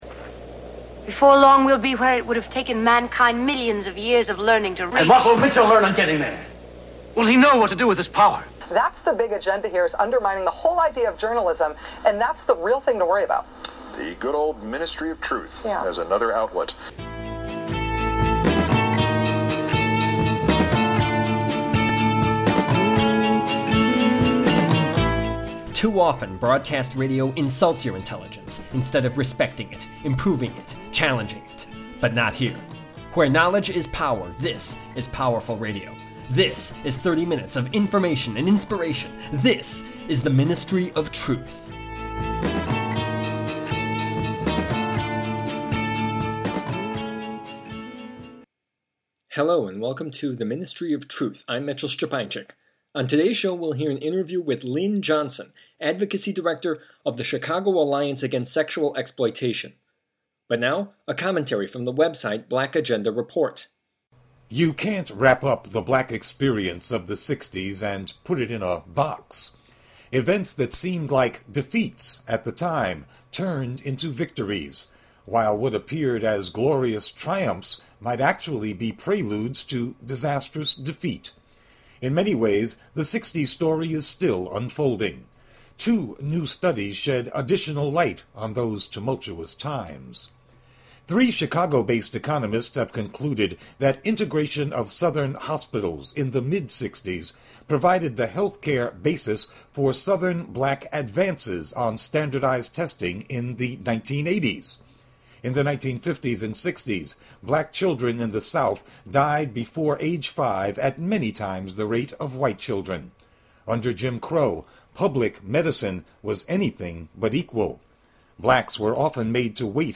The Ministry of Truth: Interview
radio